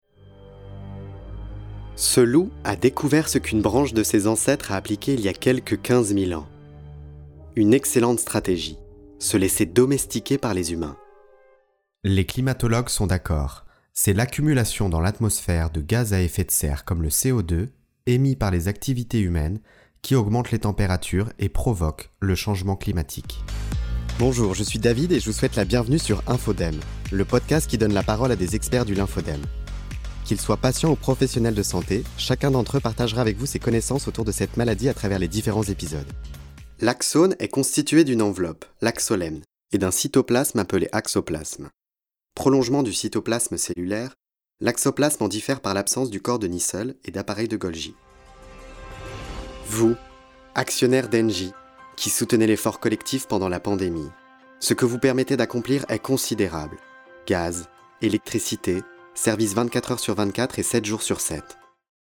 Voix off
Bande Demo
Je suis équipé d'un home studio professionnel pour enregistrer, éditer et livrer des fichiers audio HD.
voixposée